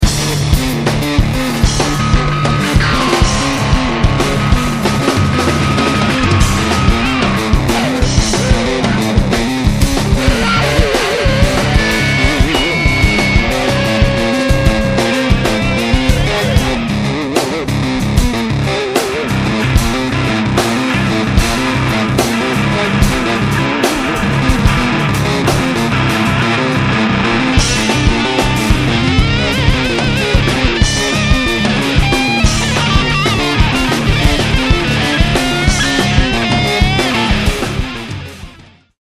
Prog/Jazz/World